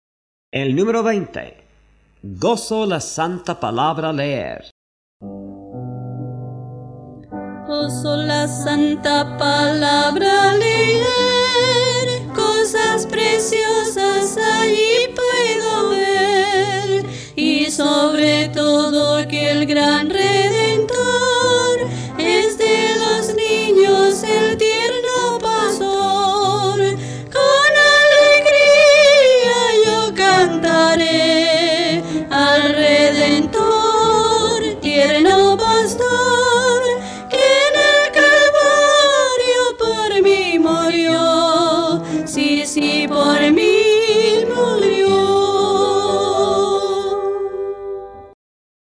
Las melodías usadas corresponden a la música original.